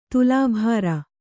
♪ tulā bhāra